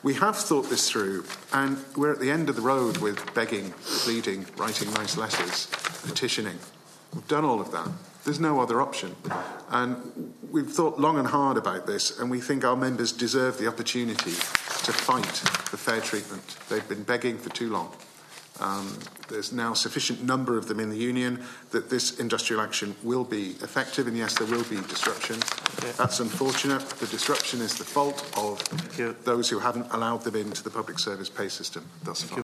The Oireachtas Committee on Education and Youth has been told a strike involving school secretaries and caretakers will get underway on August 28th.